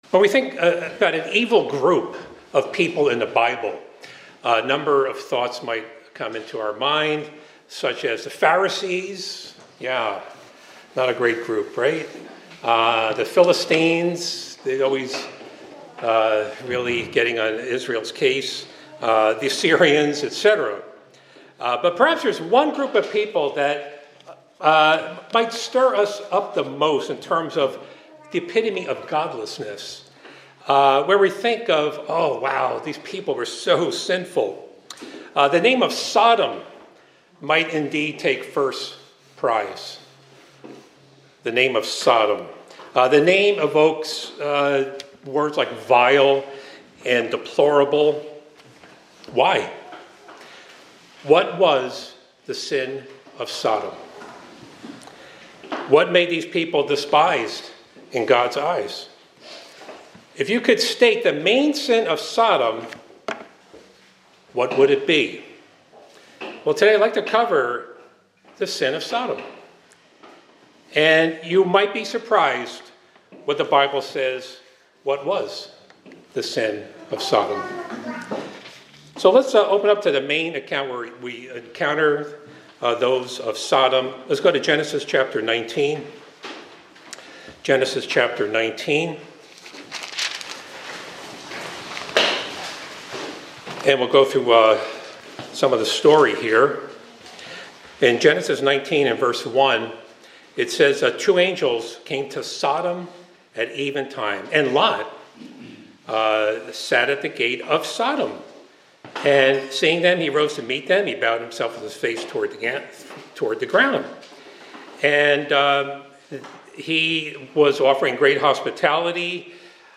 This sermon explores the various sins attributed to the people of Sodom as described in the Bible, particularly in the context of God's judgment. It discusses Sodom as a symbol of godlessness and sin, highlighting its reputation for depravity and the reasons for its destruction by God. It emphasizes that Sodom's sins are not limited to one category but encompass multiple forms of wickedness.